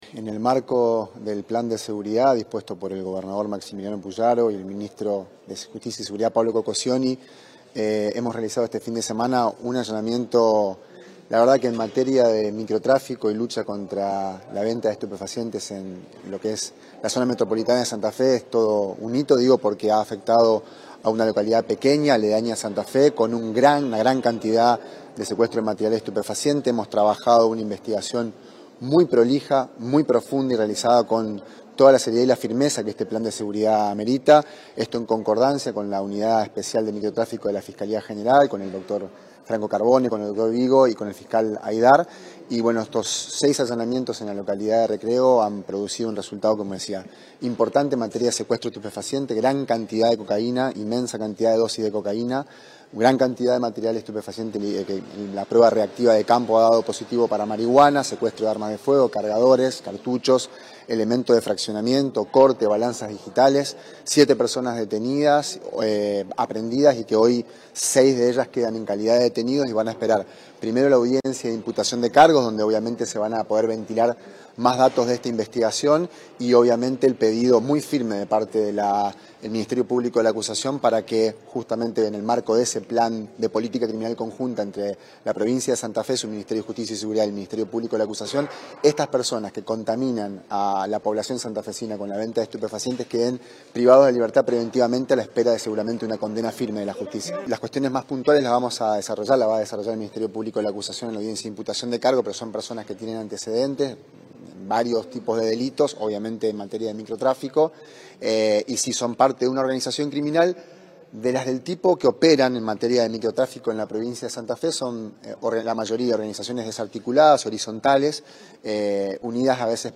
Rolando Galfrascoli, director provincial de Investigación Criminal